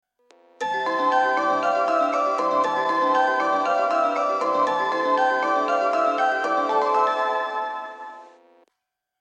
１番線発車メロディー 曲は「海岸通り」です。